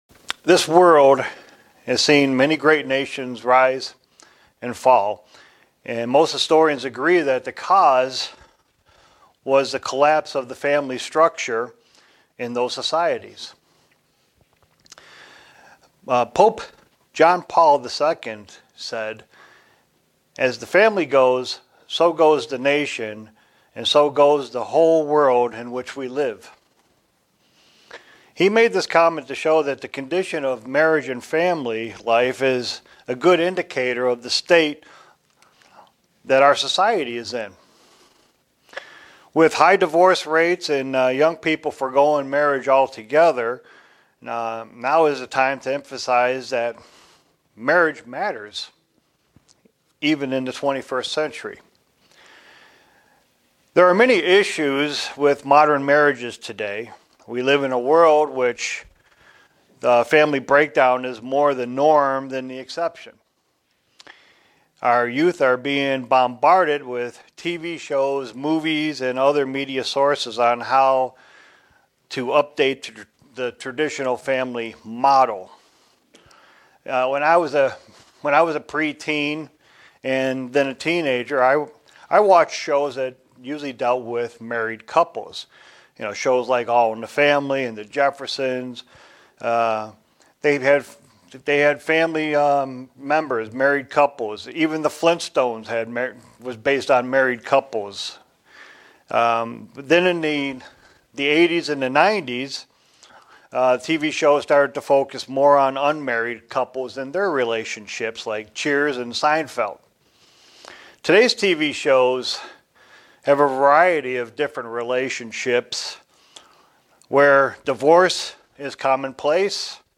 Print Gods' stance on Marriage and Divorce and how following His treatment of both of these can effect all aspects of life. sermon Studying the bible?